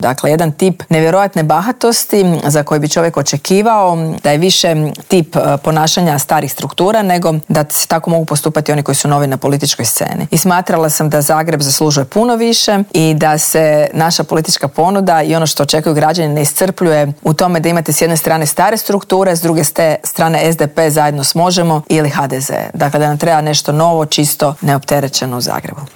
Kandidati prikupljaju potpise, a u Intervjuu tjedna Media servisa ugostili smo nezavisnu kandidatkinju Mariju Selak Raspudić.